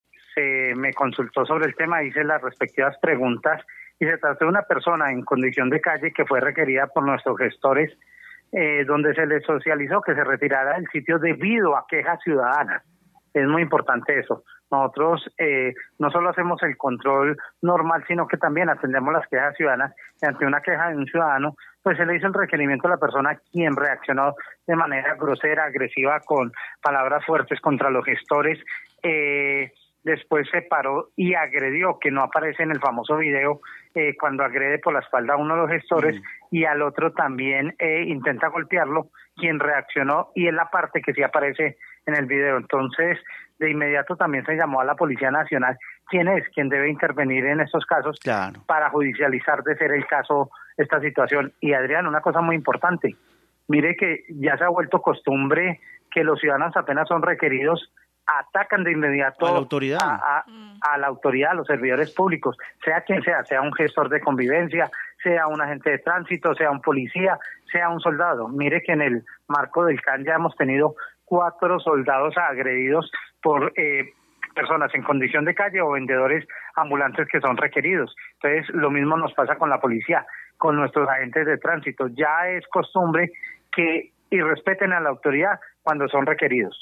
Carlos Arturo Ramírez, secretario de gobierno de Armenia y las agresiones